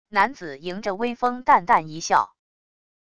男子迎着微风淡淡一笑wav音频